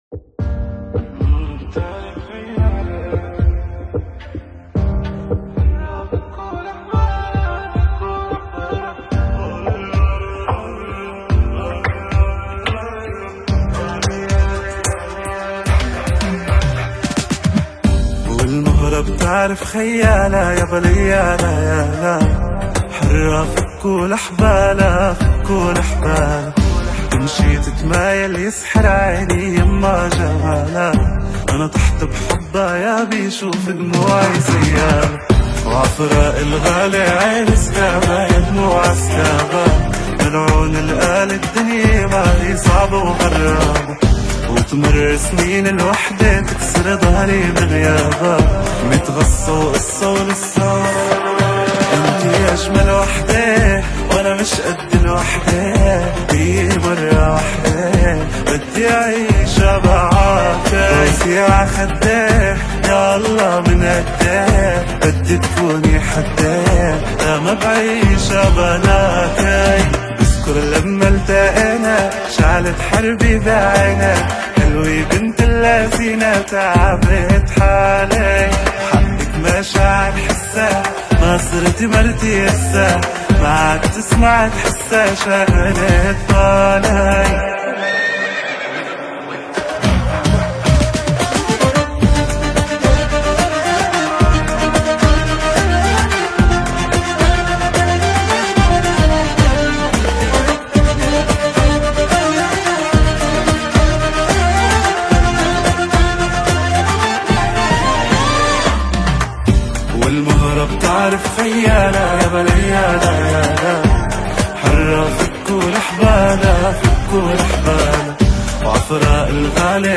• اللون الغنائي: رومانسي / بديل (Alternative).